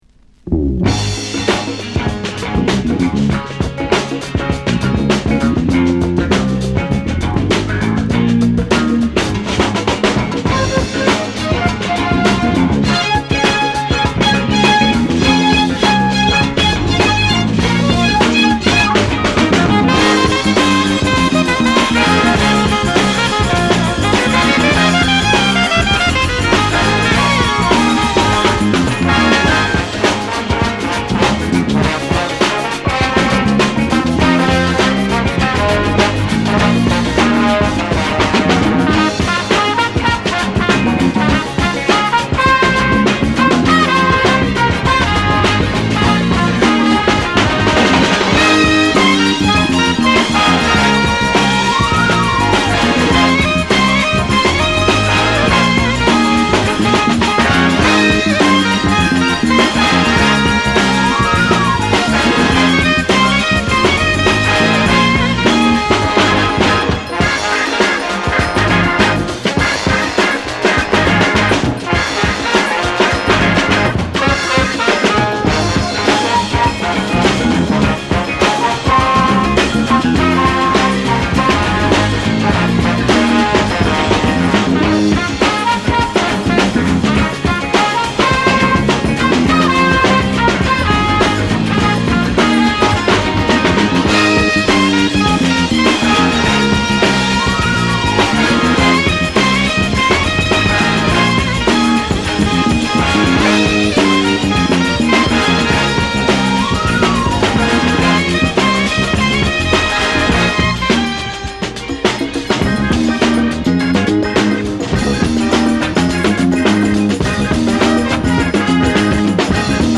ブレイクビーツ